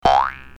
clock03.ogg